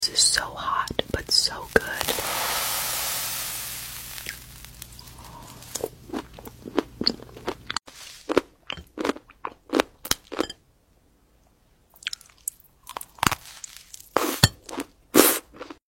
Lava asmr mukbung 🤖 🫢 sound effects free download